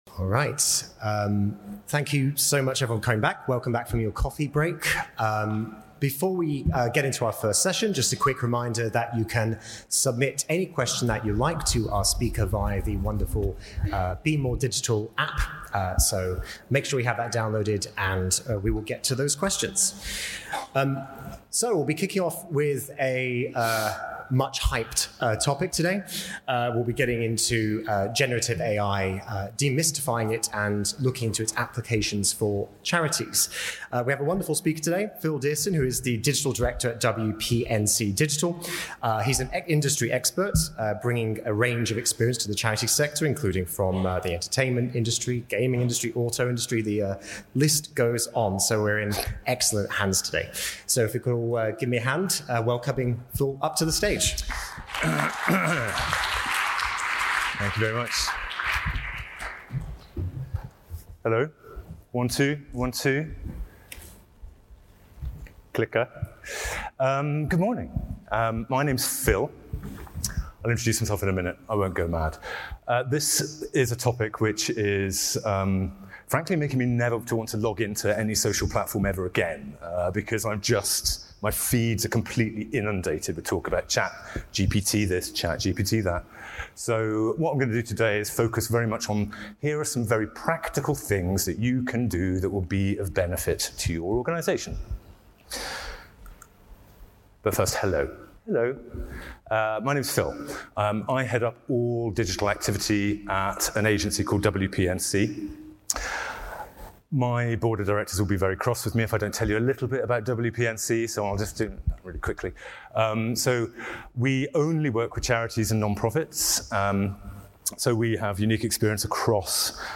In this podcast, taken from a recording from our 2023 Conference, we aim to demystify artificial intelligence (AI), explain some of the best ways that charities can use it, illustrate some of the core benefits for charities, and suggest some of the ways you should practice cau...